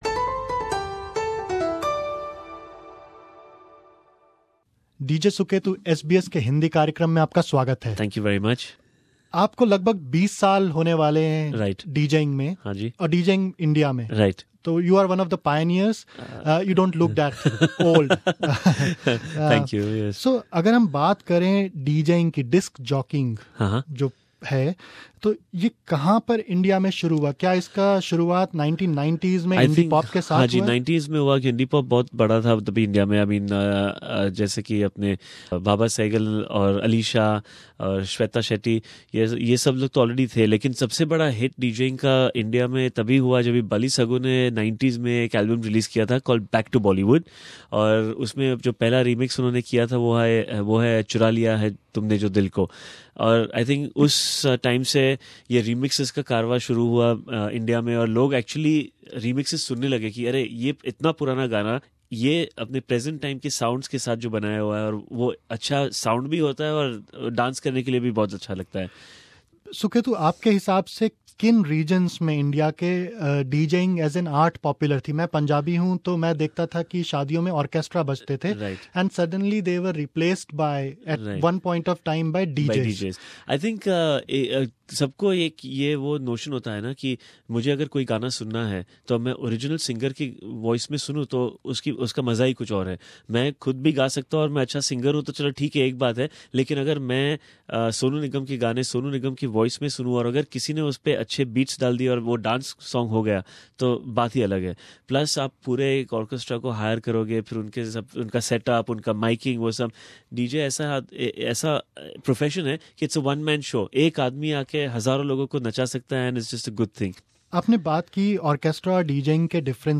DJ Suketu at SBS studio Source